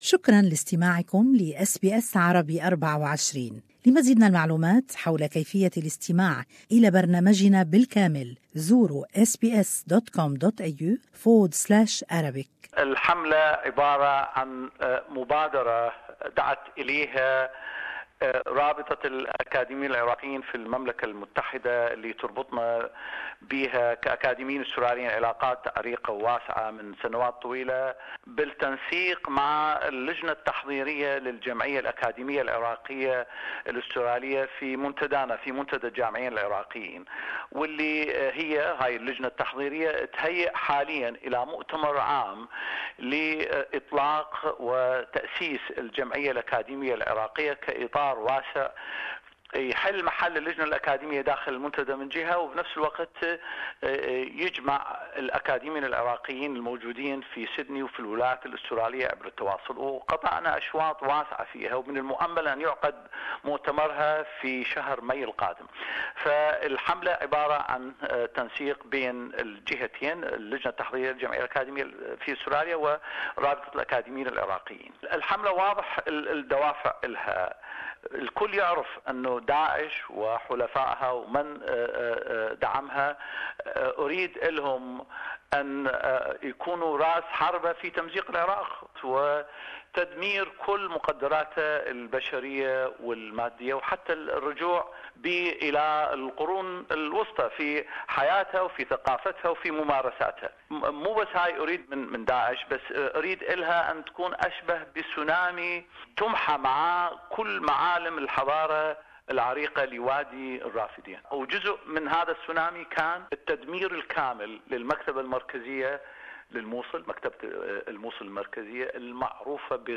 The Australian Iraqi Graduates Forum has launched a campaign to rehabilitate Mosul Libraries. More is in this interview